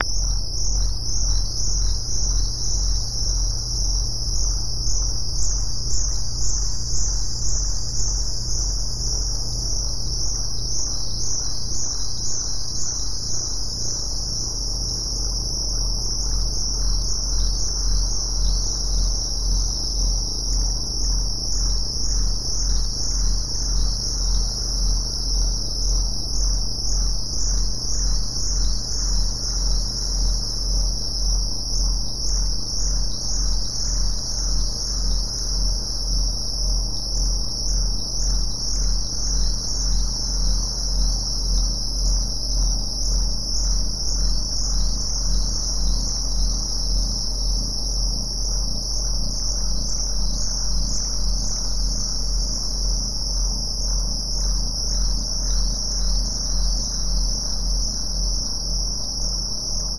Mine Finder, Machine, Deep Ambient, Space, Engine, Pulse, Electric, Atmosphere